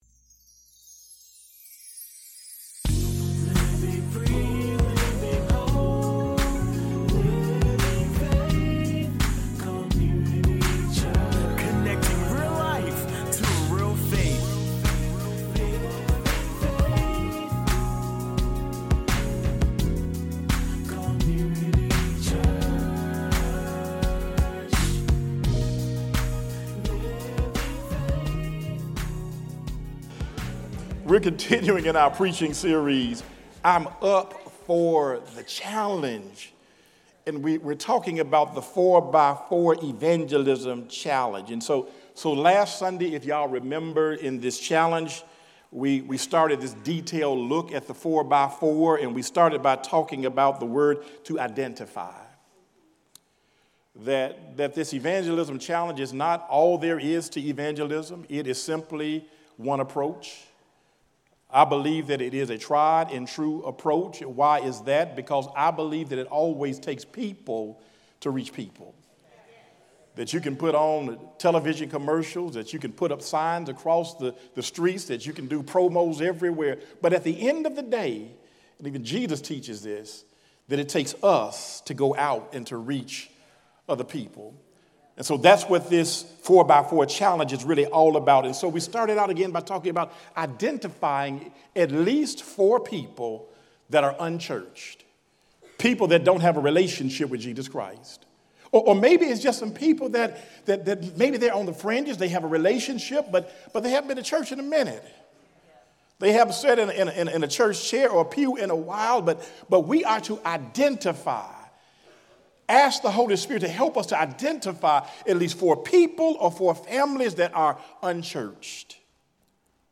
Audio Sermons | Living Faith Community Church